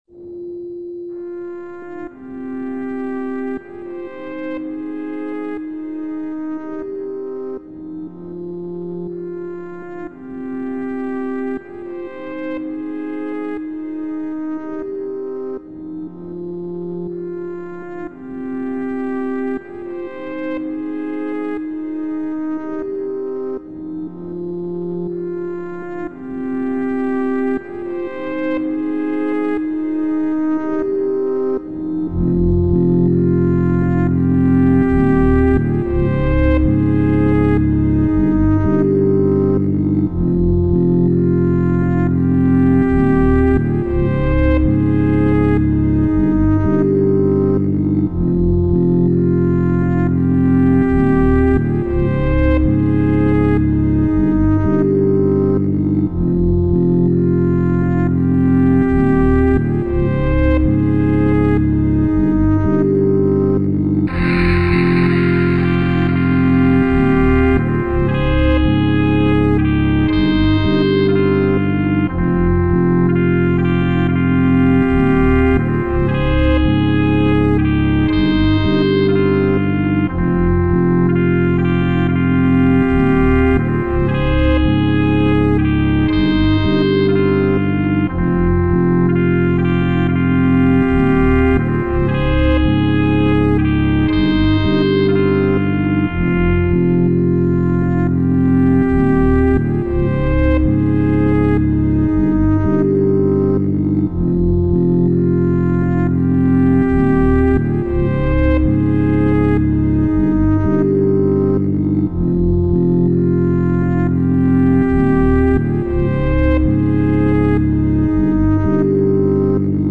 17 experimental noise and instrument valley assemblies